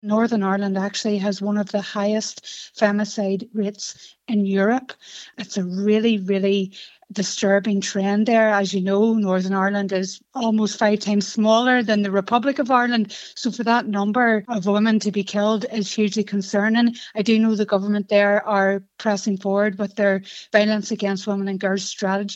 Investigative reporter